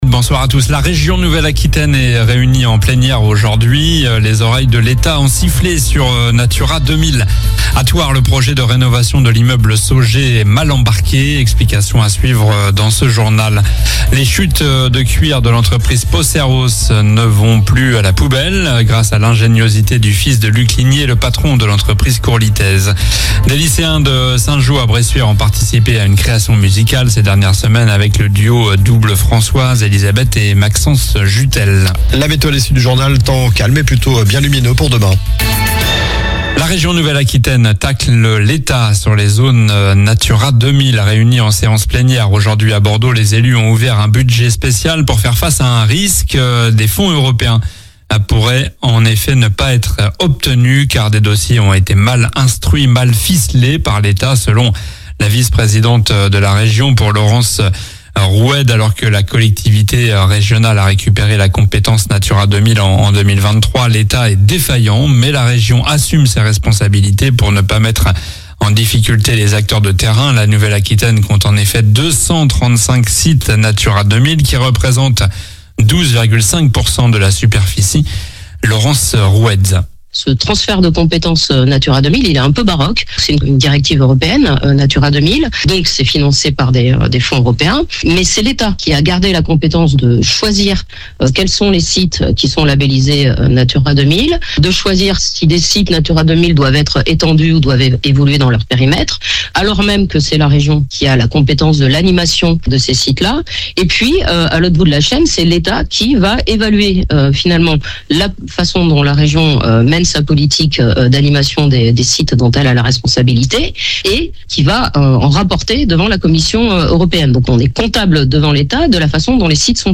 Journal du lundi 17 mars (soir)